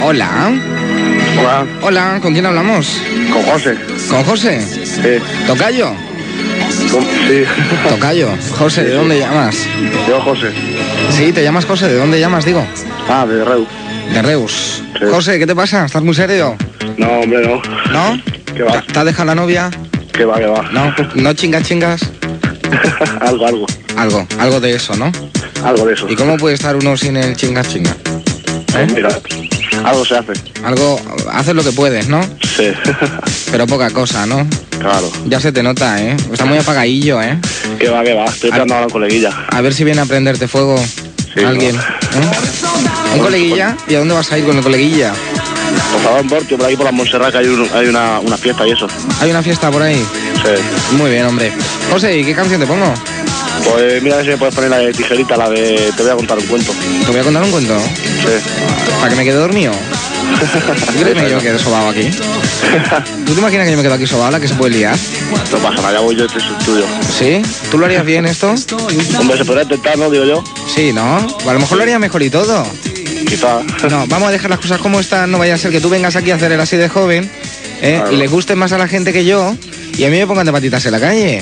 Conversa telefònica amb un oient de Reus i petició musical.
Musical
FM